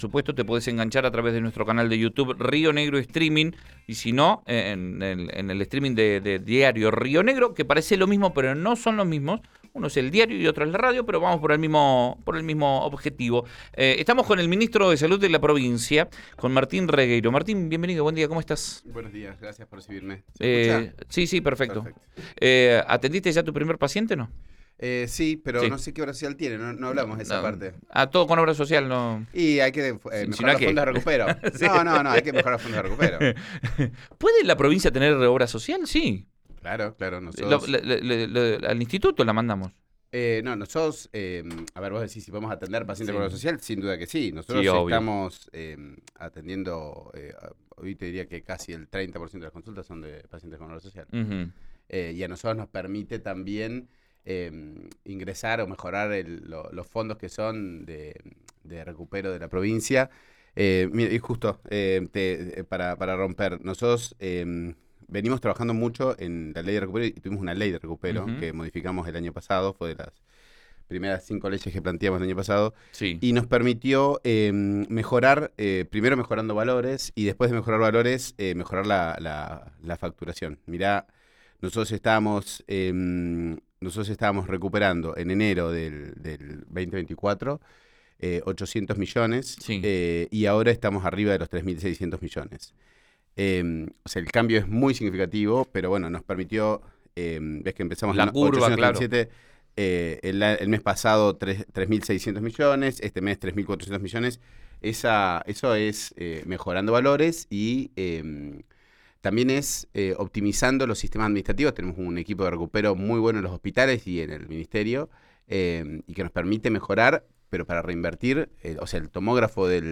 Martín Regueiro habló en Río Negro Radio sobre el caso en la localidad cordillerana y dijo que no 'hay que alarmarse'.
Escuchá a Martín Regueiro, ministro de Salud de Neuquén, en RN Radio:
Regueiro amplió en diálogo con Panorama Informativo que se trata de un caso "con diagnóstico clínico".